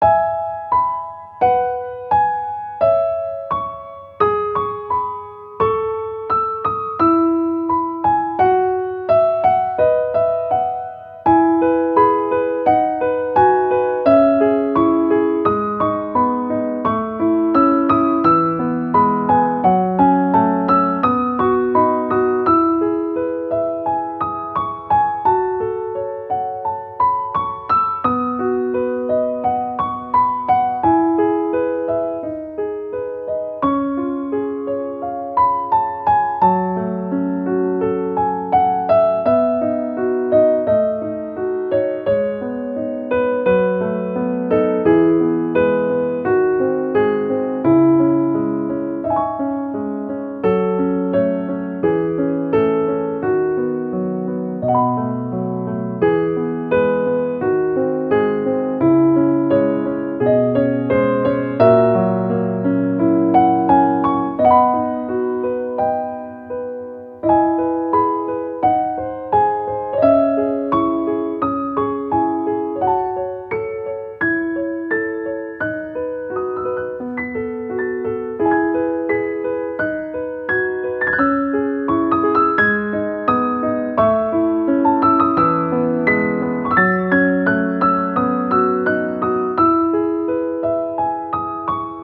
• 暗めのしっとりしたピアノ曲のフリー音源を公開しています。
ogg(L) 楽譜 甘美 切ない かわいい